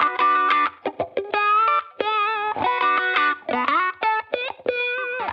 Index of /musicradar/sampled-funk-soul-samples/90bpm/Guitar
SSF_StratGuitarProc1_90B.wav